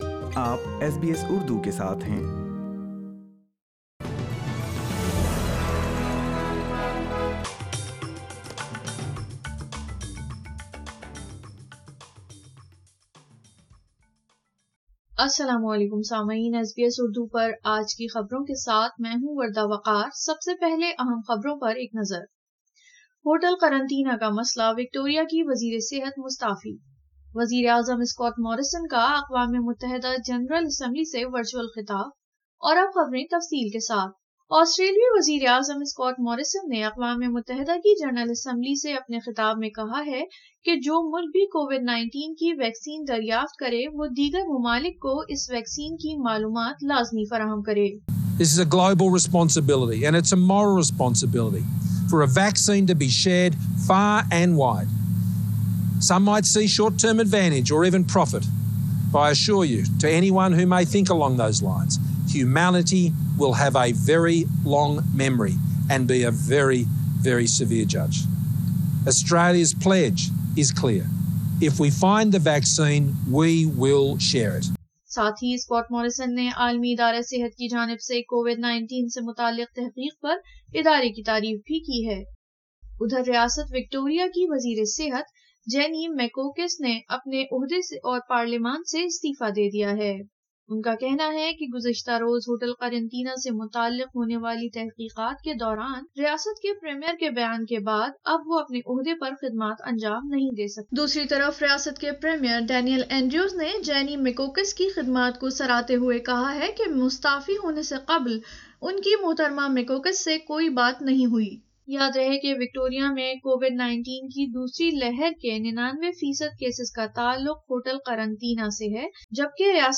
اردو خبریں 26ستمبر 2020